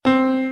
Piano Keys C Scale New
c2.wav